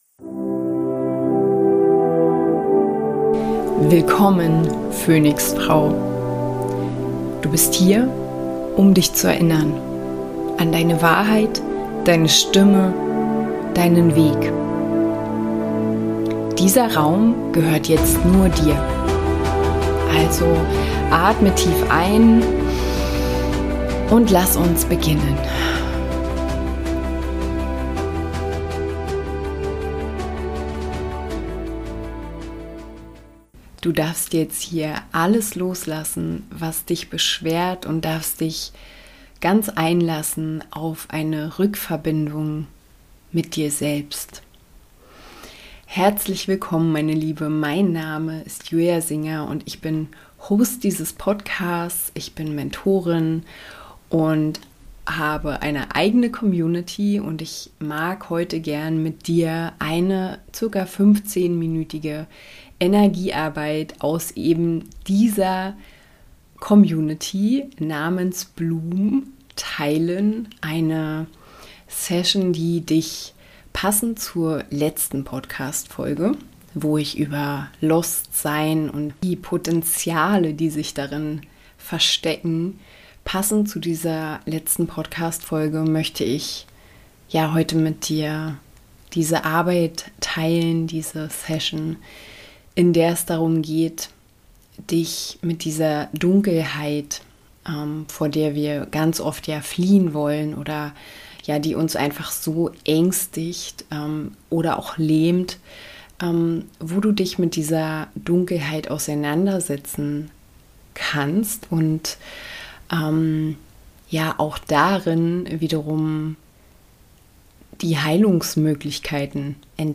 Eine Meditation für Vertrauen & innere Stärke ~ PHÖNIX-FRAUEN: Sensibel. Ungezähmt. Frei. Podcast
Diese Folge schenkt Dir eine geführte Energiearbeit-Session aus meiner Community BLOOM: eine Einladung, durch die Dunkelheit zu gehen – statt vor ihr wegzulaufen.